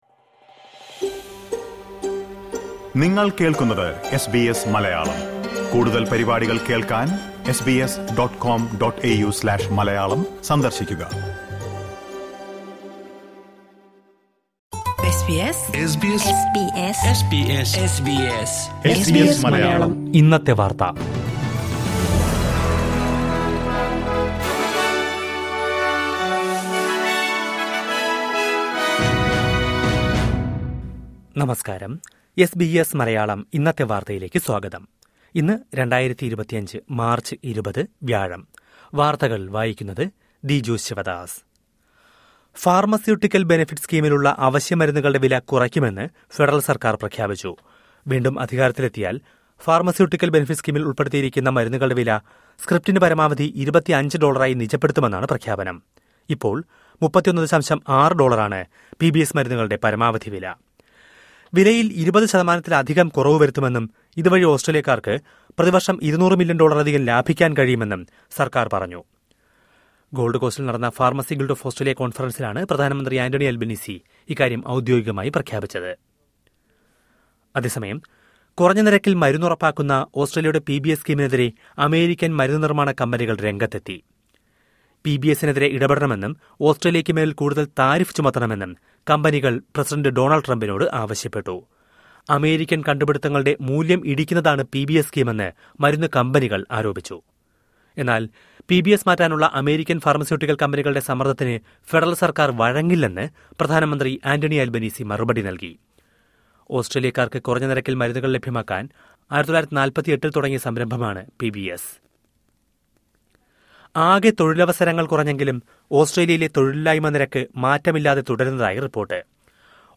2025 മാര്‍ച്ച് 20ലെ ഓസ്‌ട്രേലിയയിലെ ഏറ്റവും പ്രധാന വാര്‍ത്തകള്‍ കേള്‍ക്കാം...